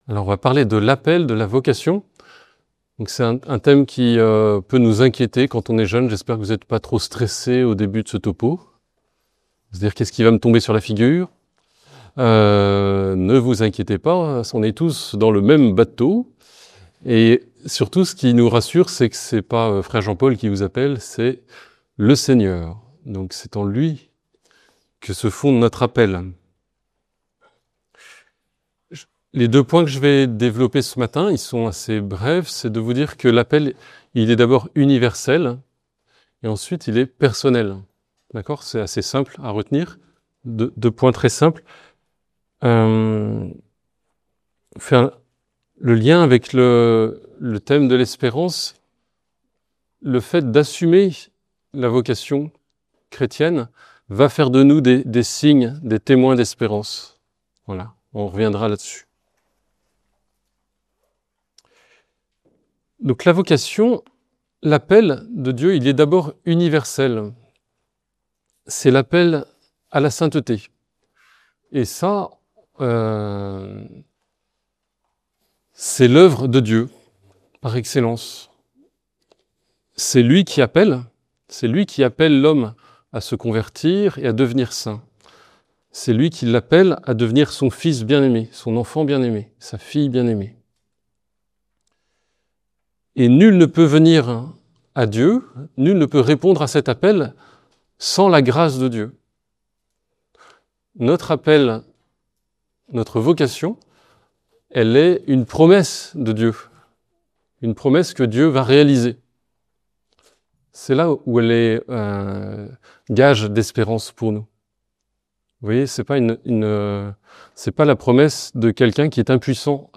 La vocation et Homélie